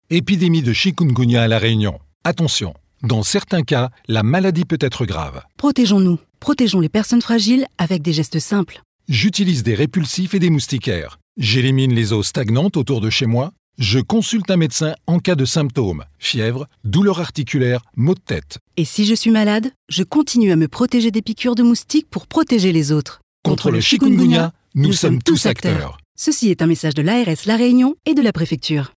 Spot radio chik Avril 2025.mp3